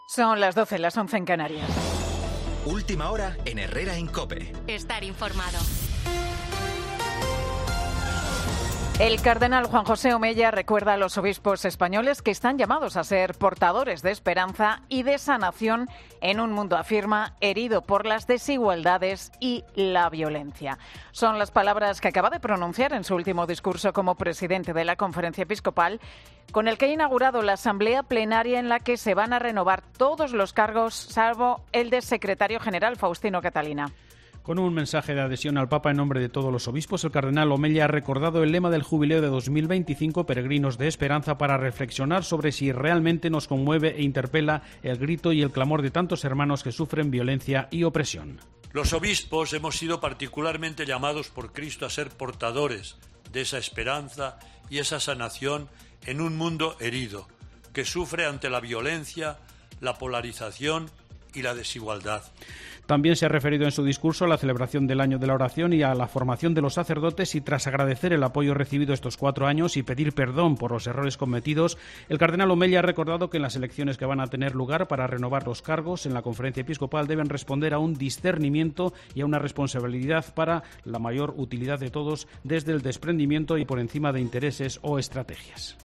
En la apertura de la Asamblea Plenaria de la Conferencia Episcopal, el cardenal Juan José Omella pide unidad a todos los obispos y comunión con el Papa...